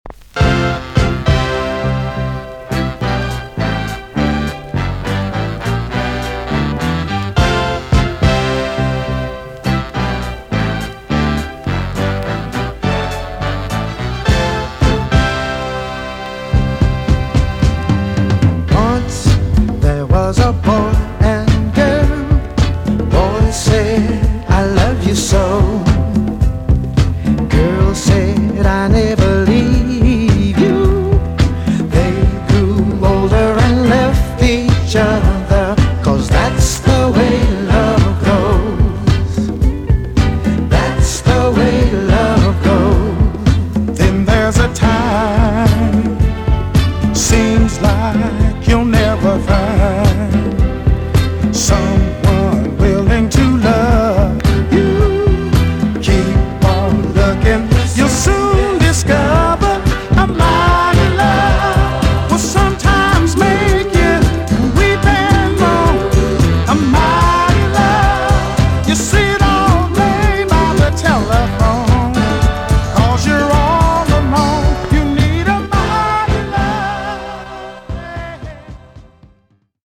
EX-音はキレイです。
1973 , WICKED SOUL CLASSIC TUNE!!